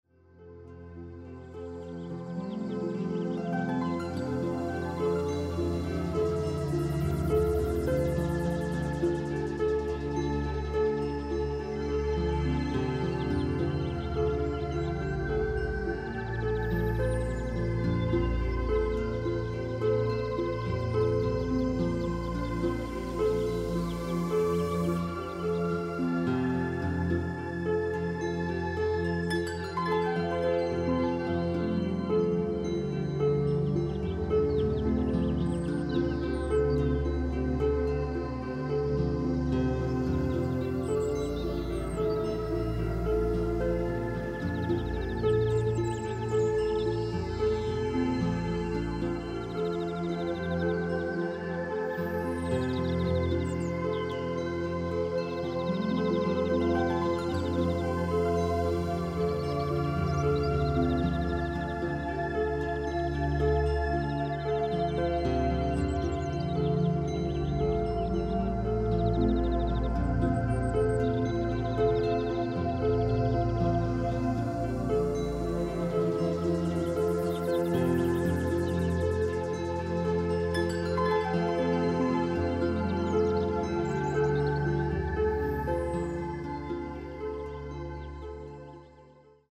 Reiner Klang   10:28 min